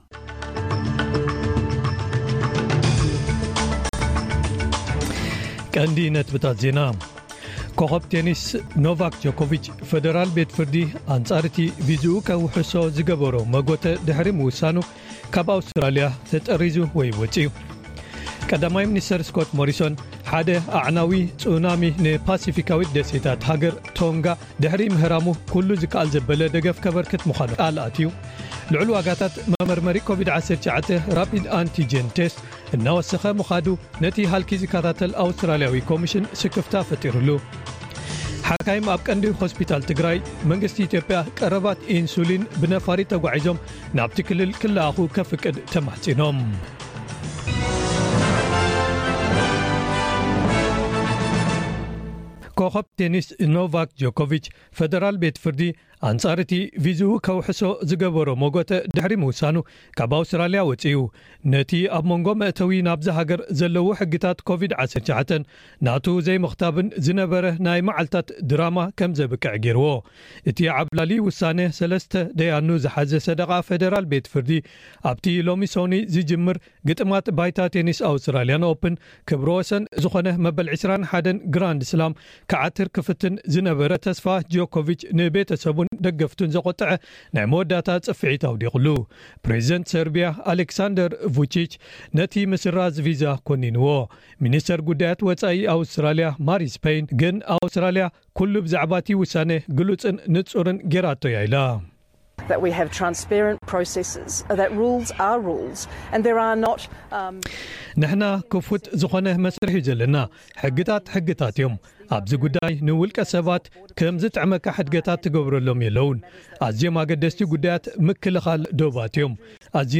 ዕለታዊ ዜና ኤስቢኤስ ትግርኛ * ሓካይም ኣብ ቀንዲ ሆስፒታል ትግራይ መንግስቲ ኢትዮጵያ ቀረባት ኢንሱሊን ብነፋሪት ተጓዒዞም ናብ’ቲ ክልል ክለኣኹ ከፍቅድ ተማሕጺኖም።